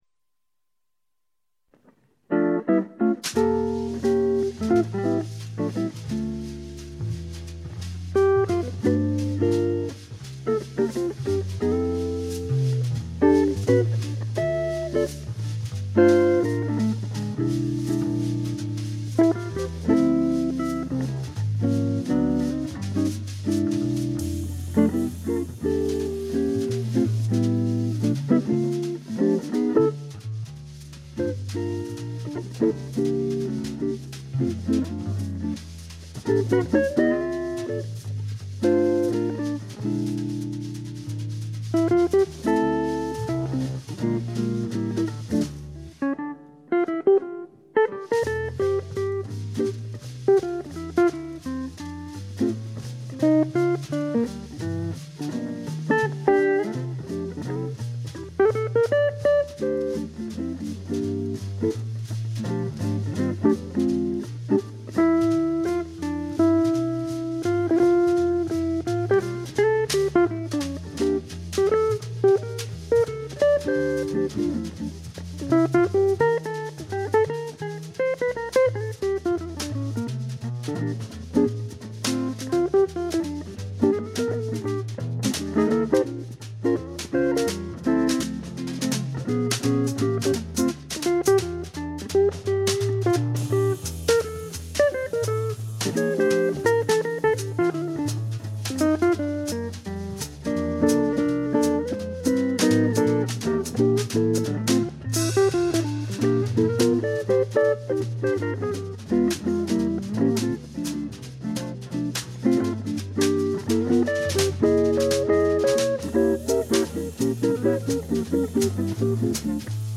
Gepflegter Gitarren-Jazz.
Im Trio mit Kontrabass und Schlagzeug